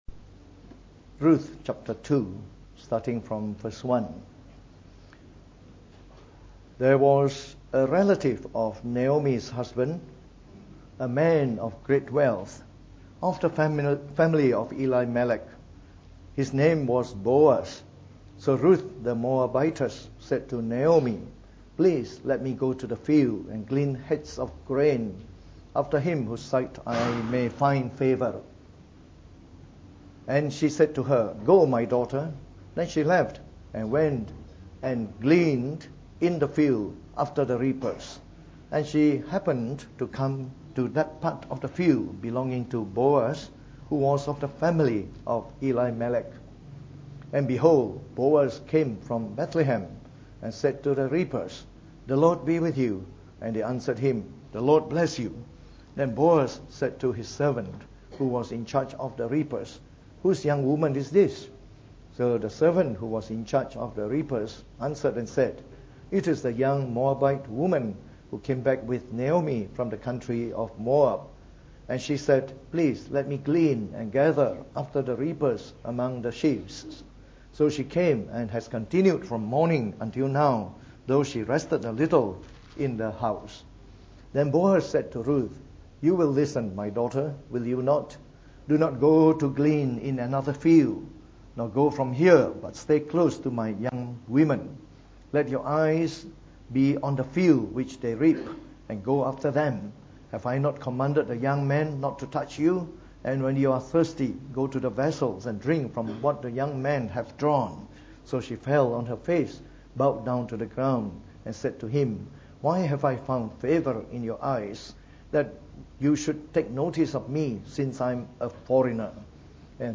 From our series on the Book of Ruth delivered in the Morning Service.